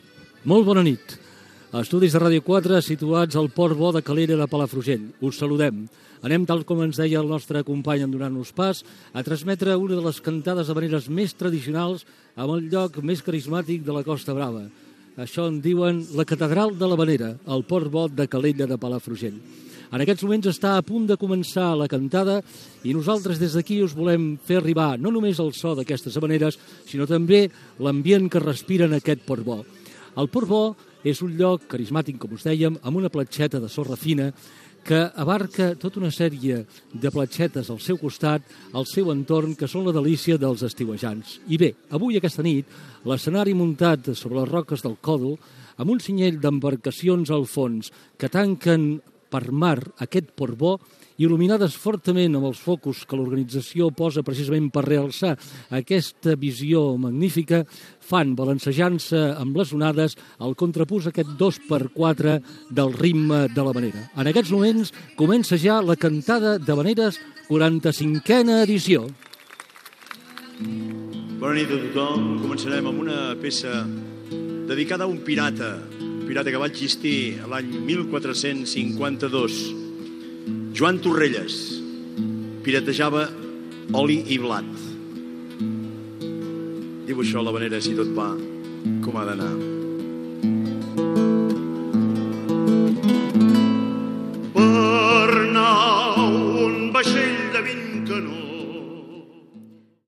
Inici de la transmissió, des de Calella de Palfrugell, de la 45ena Cantada d'Havaneres
Musical